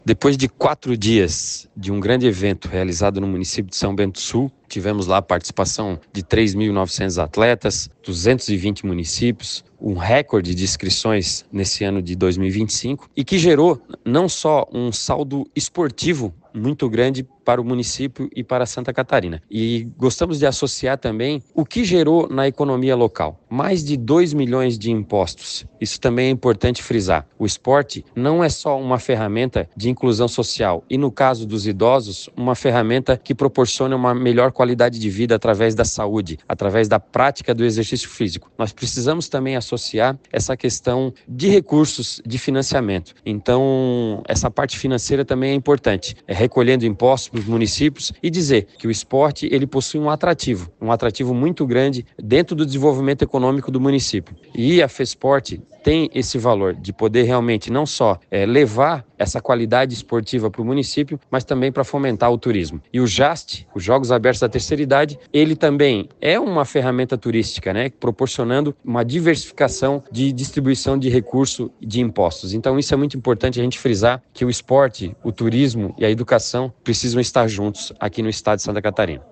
O presidente da Fesporte faz um balanço da competição e ressalta a importância esportiva e também econômica de eventos como o Jasti: